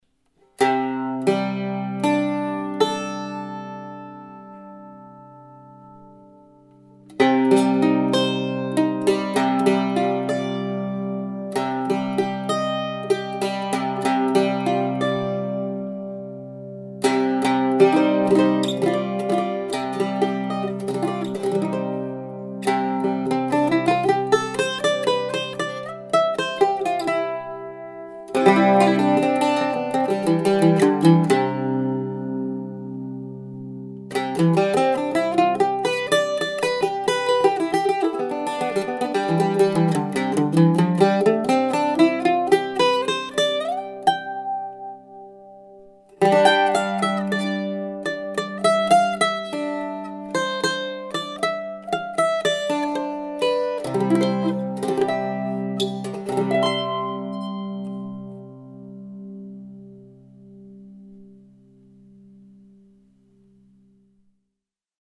Gibson (USA) H2 Mandola - Teens A
Gibson_H2Mandola_2.mp3